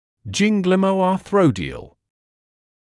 [ˌʤɪnglɪməɑː’θrɔdɪəl], [ˌgɪnglɪməɑː’θrɔdɪəl][ˌджинглимэаː’сродиэл], [ˌгинглимэаː’сродиэл]гинглимоартродиальный